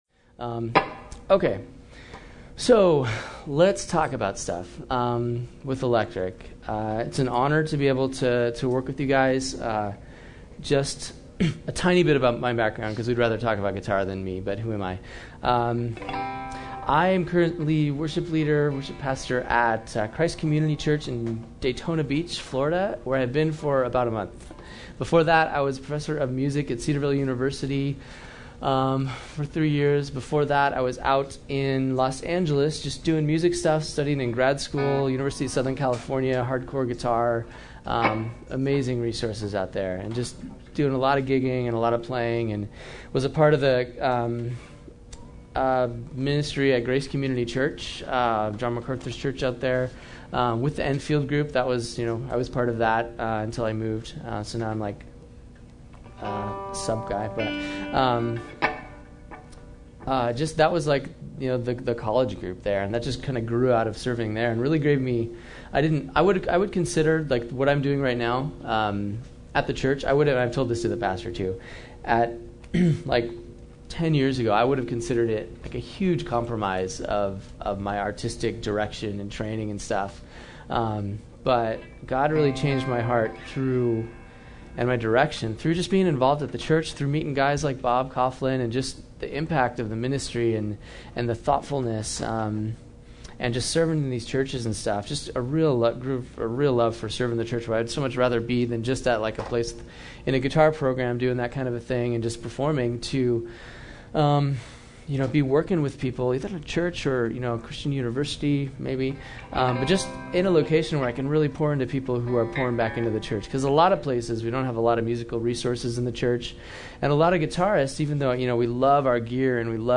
Seminars